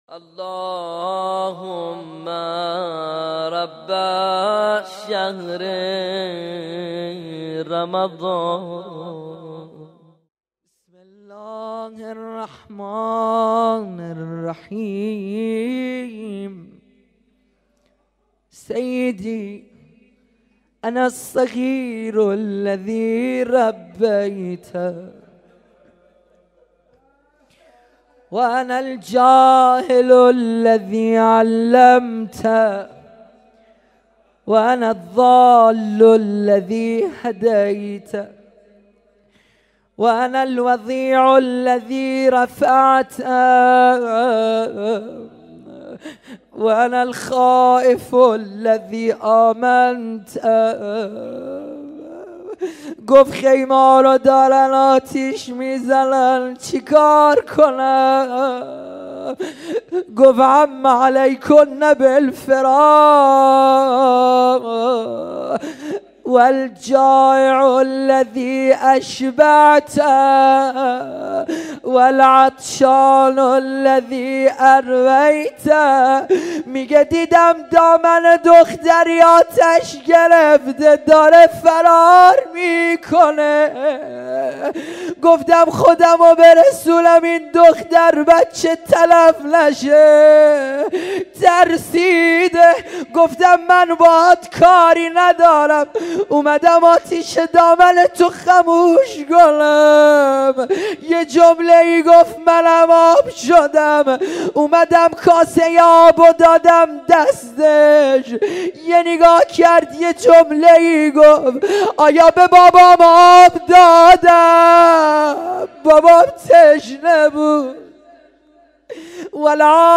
قرائت دعای أبو حمزه الثمالی (بخش چهارم)